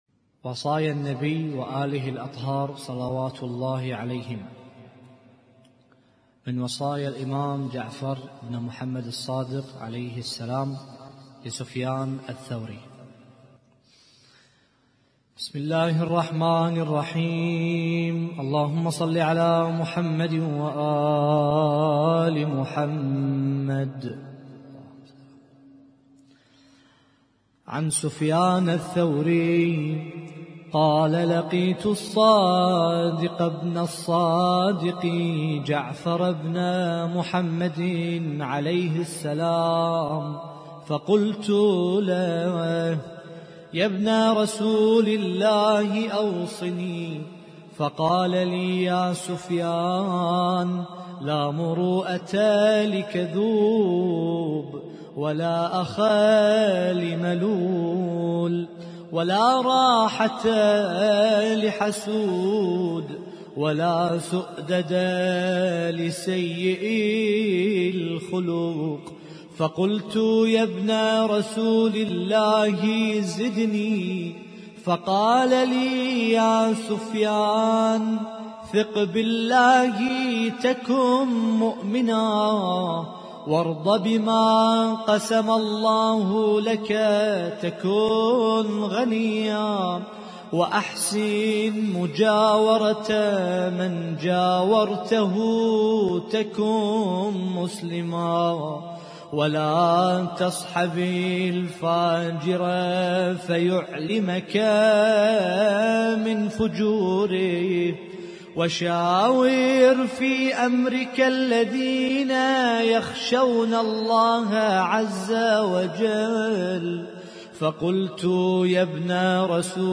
القارئ: